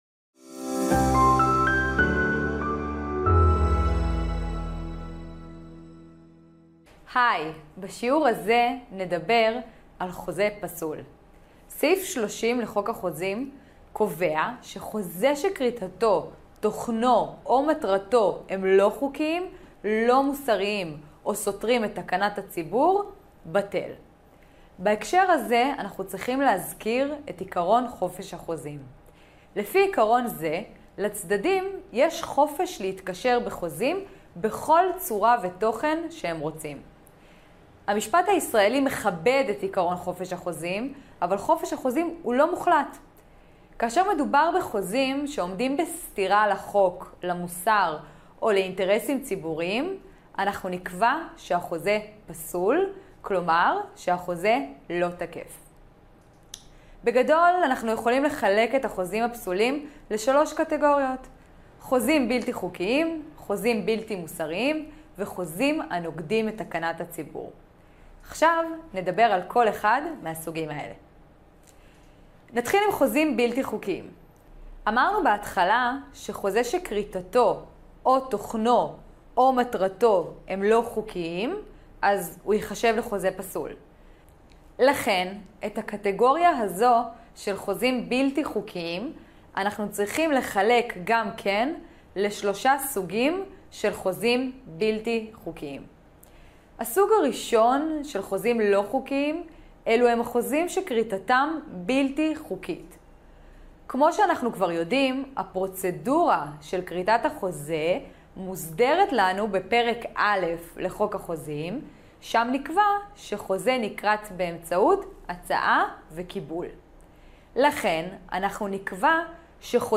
שיעור לדוגמה מתוך הקורס המלא בדיני חוזים 💫